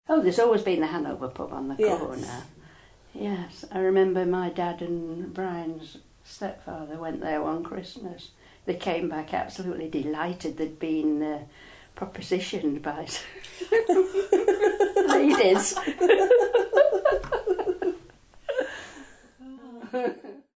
In these audio clips below, past and present residents recollect the names of some of these ‘disappeared’ pubs, and tell some stories about what used to go on…